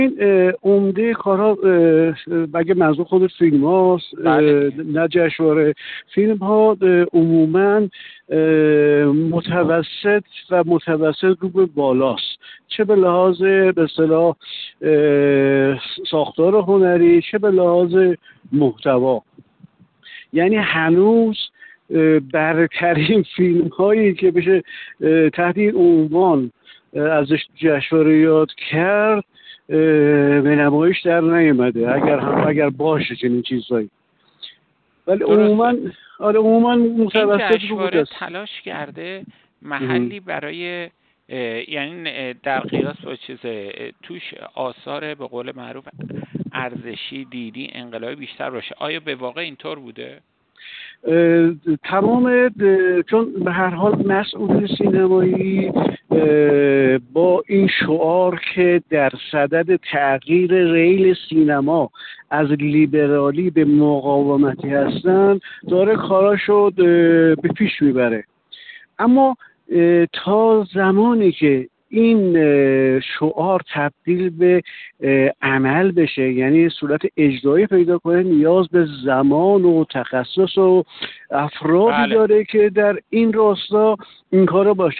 یک منتقد سینما:
در حاشیه برگزاری چهل‌و‌دومین جشنواره بین‌المللی فیلم فجر در گفت‌وگو با خبرنگار ایکنا